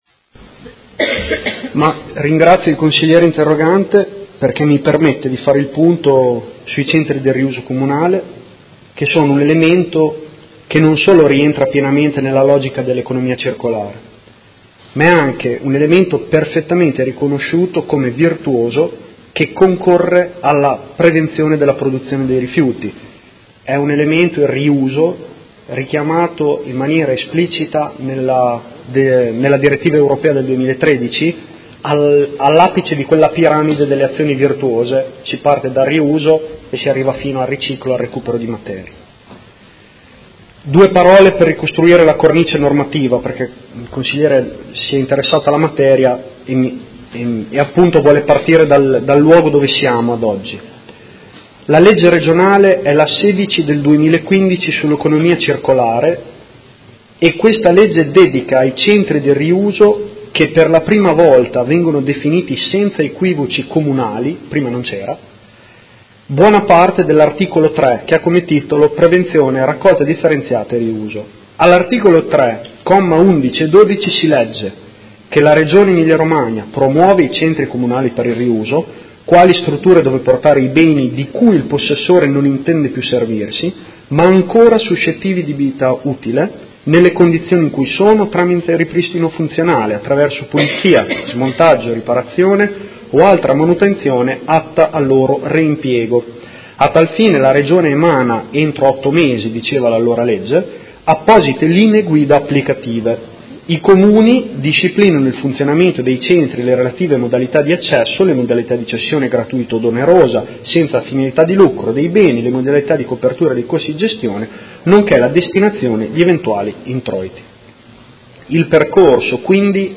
Seduta del 19/10/2017 Risponde. Interrogazione del Consigliere Rocco (Art.1-MDP) avente per oggetto: Sostegno all’economia circolare